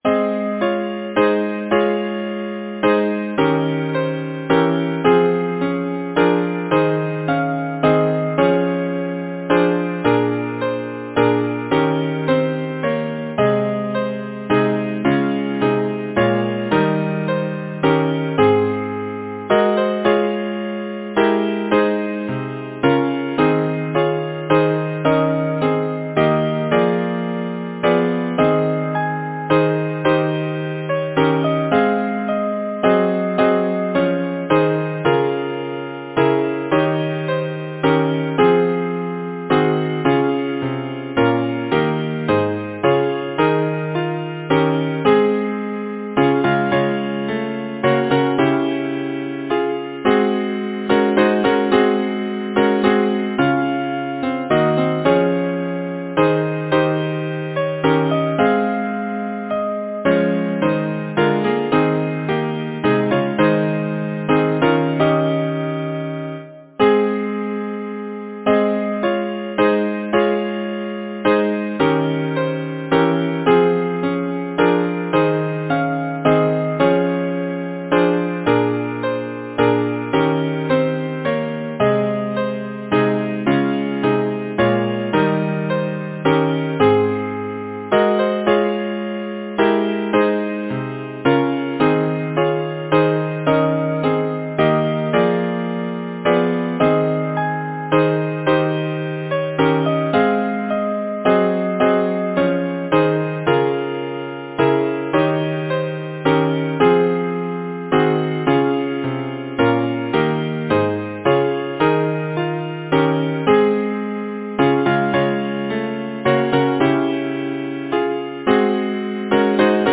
Title: Lullaby Composer: William Archibald Howells Lyricist: James Cobb Number of voices: 4vv Voicing: SATB Genre: Secular, Partsong
Language: English Instruments: A cappella